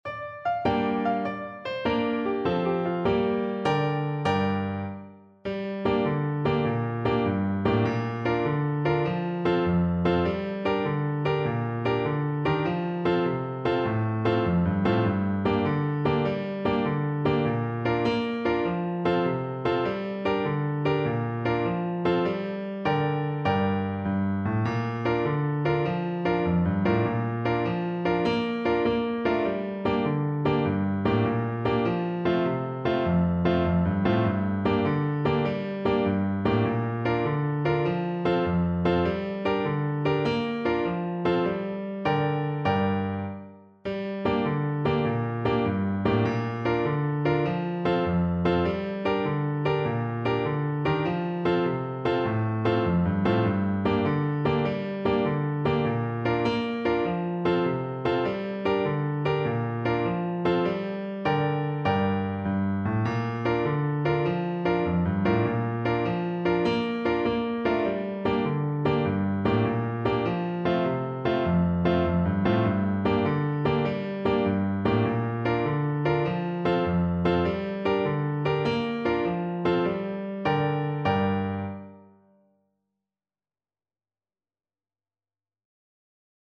Play (or use space bar on your keyboard) Pause Music Playalong - Piano Accompaniment Playalong Band Accompaniment not yet available transpose reset tempo print settings full screen
Clarinet
6/8 (View more 6/8 Music)
G minor (Sounding Pitch) A minor (Clarinet in Bb) (View more G minor Music for Clarinet )
Allegro .=c.100 (View more music marked Allegro)
Traditional (View more Traditional Clarinet Music)